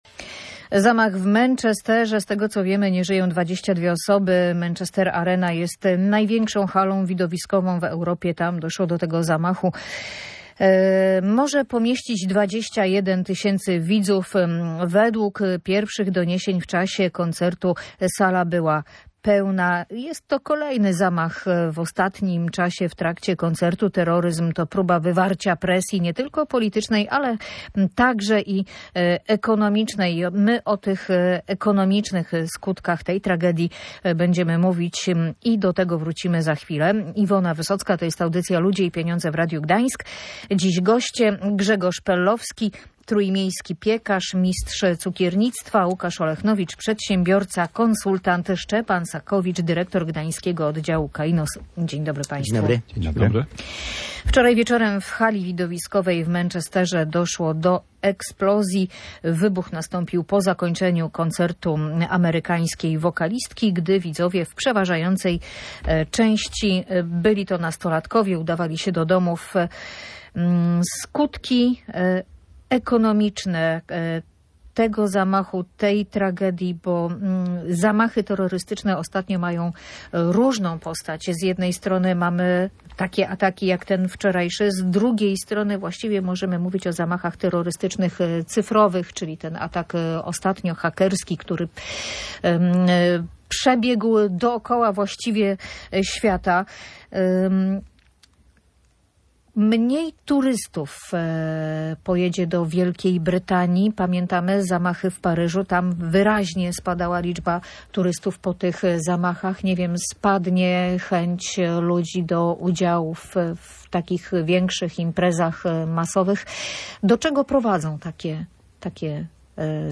Jak takie incydenty wpływają na ekonomię? Rozmawiali o tym goście audycji Ludzie i Pieniądze.